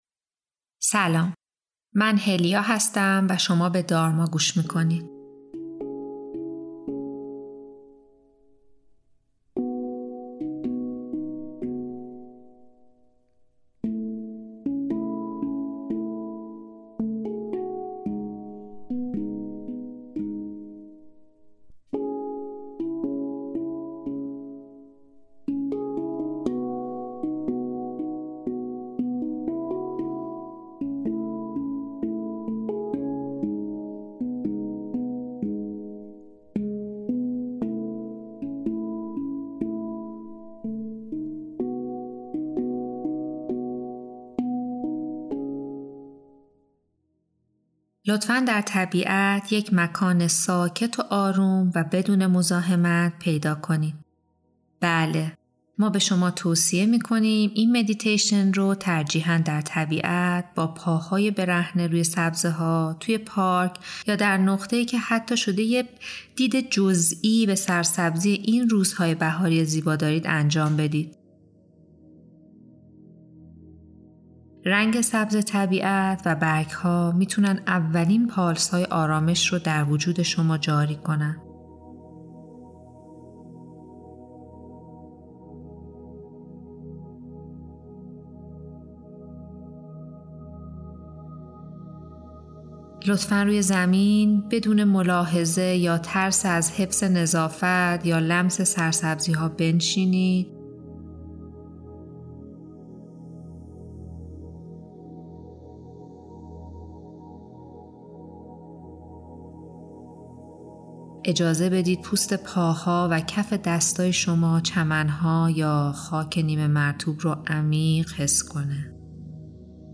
نو شدن - مدیتیشن در طبیعت 1400